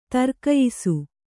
♪ tarkayisu